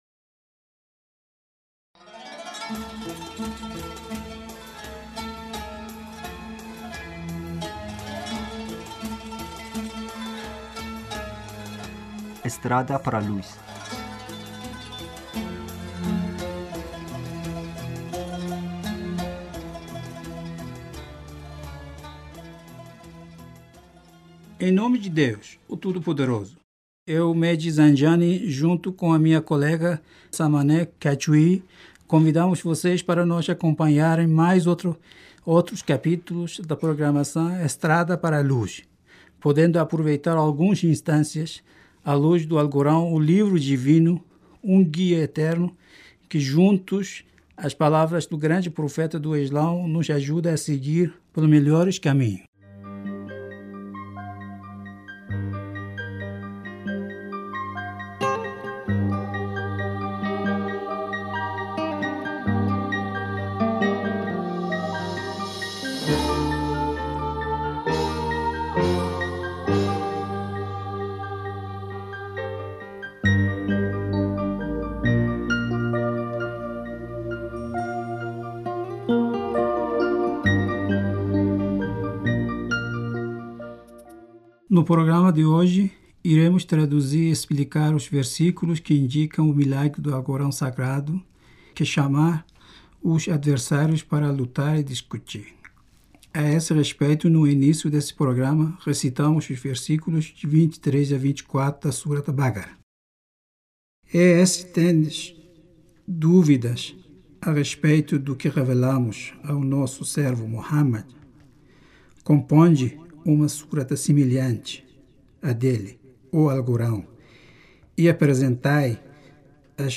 A este respeito no inicio deste programa recitando os versículos de 23 e 24 da surata de Bácara.